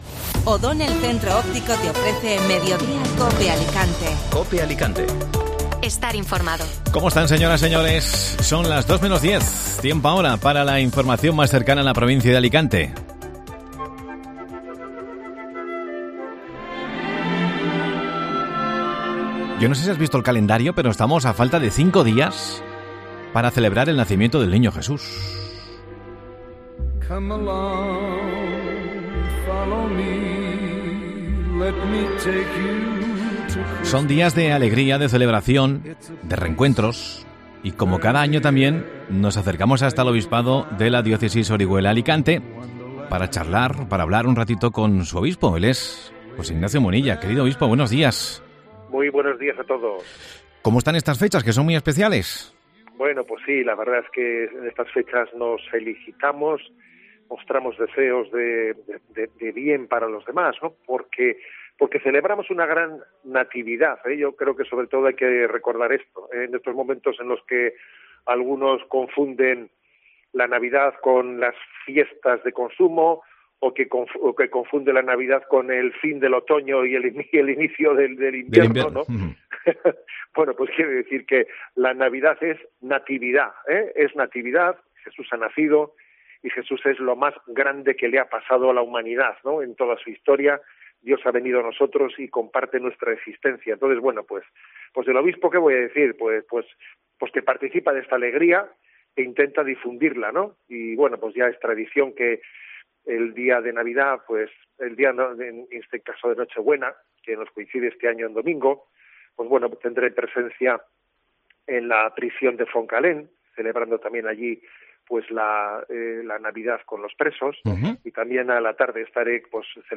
AUDIO: Entrevista con Mons.José Ignacio Munilla, Obispo de la Diócesis Orihuela- Alicante.